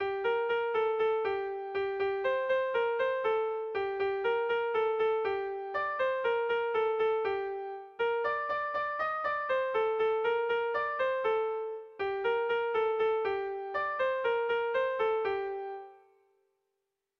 Erromantzea
Zortzikoa, txikiaren moldekoa, 4 puntuz (hg) / Lau puntukoa, txikiaren modekoa (ip)
ABDB